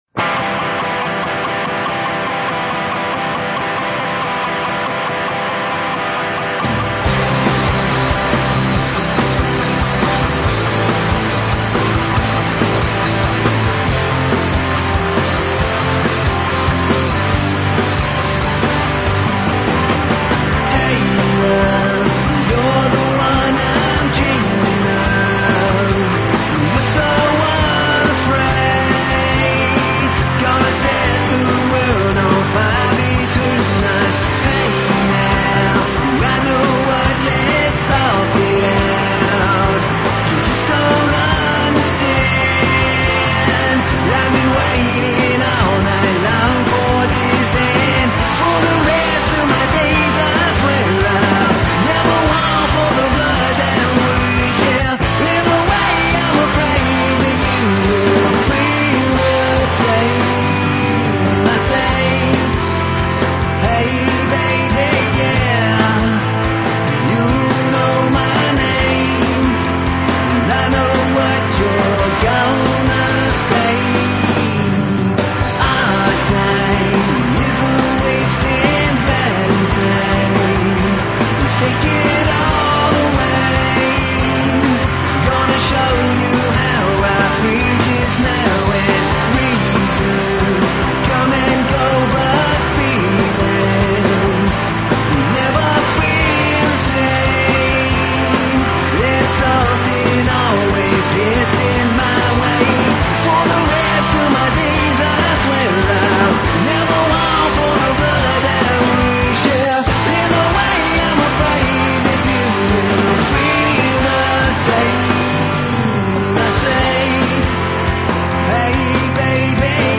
rock
punk
metal
high energy rock and roll